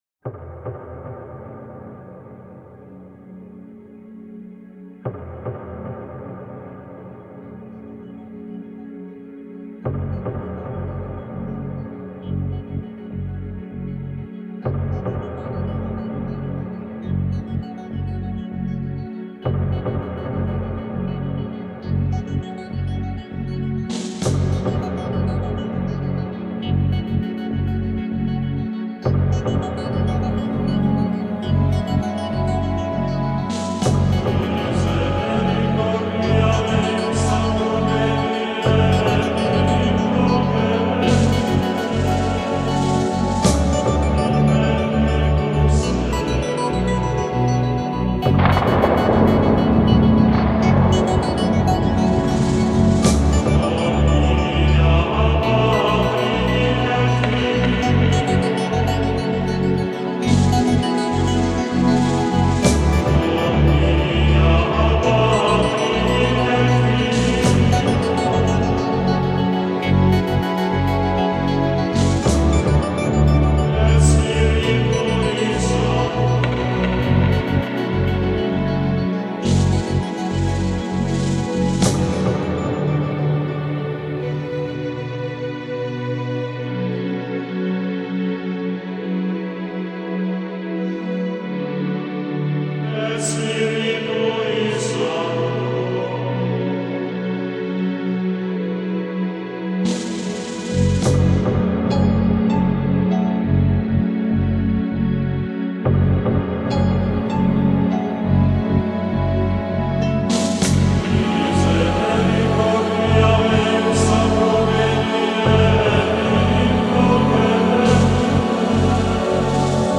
Genre: Enigmatic.